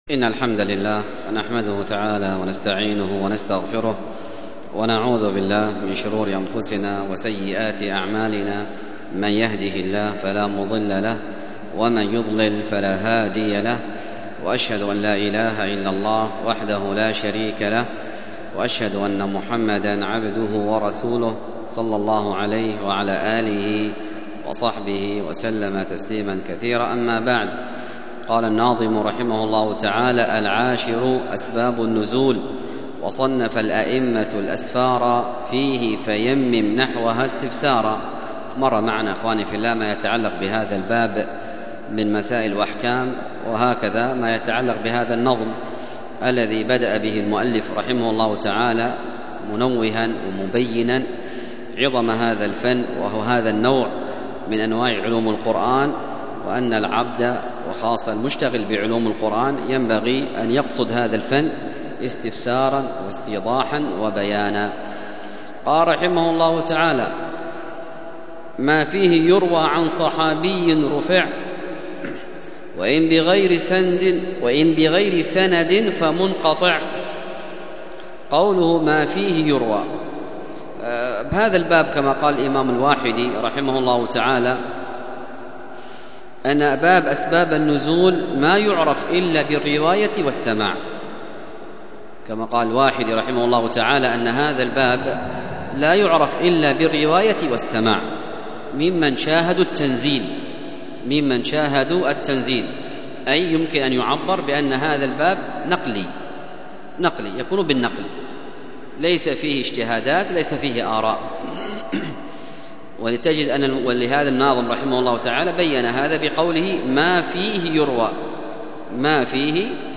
الدرس في التعليق على العقيدة الطحاوية 86، ألقاها